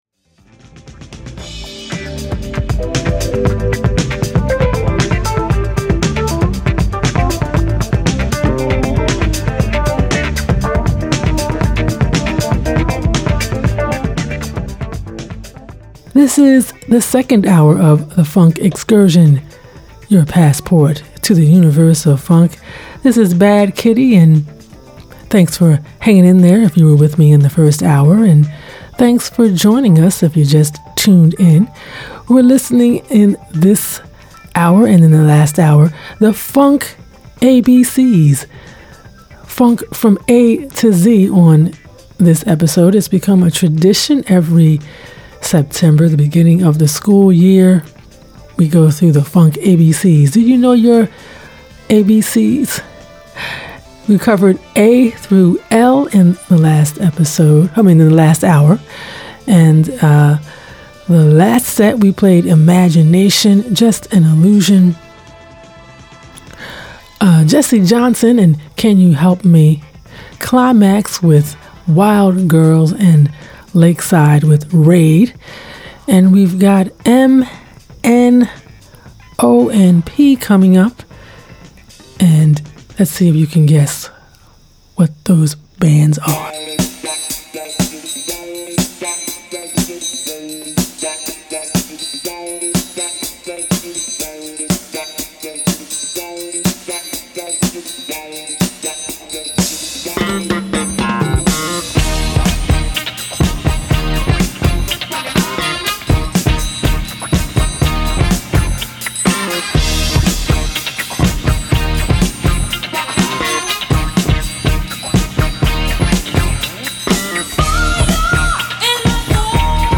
plays the Funk from A-Z!
classic, new and rare funk bands and grooves